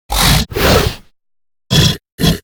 Sfx_creature_snowstalker_standup_sniff_01.ogg